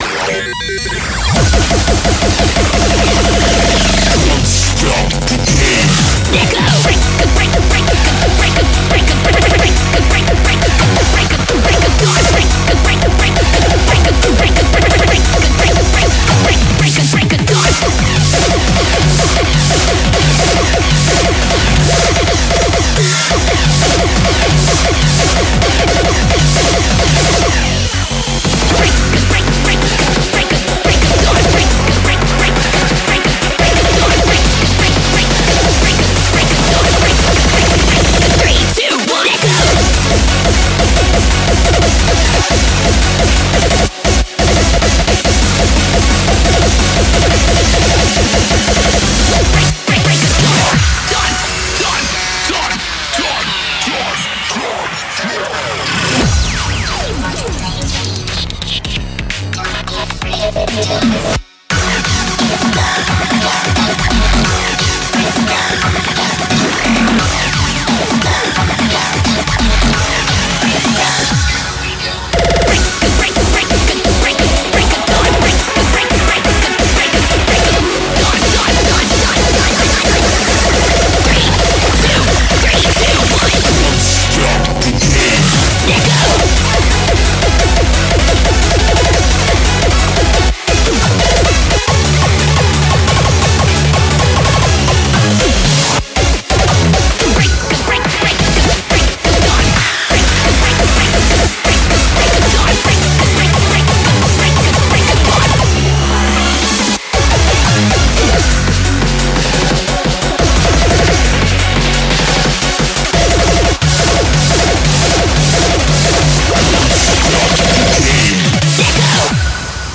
BPM175
Audio QualityPerfect (High Quality)
Comments[MAINSTREAM HARDCORE]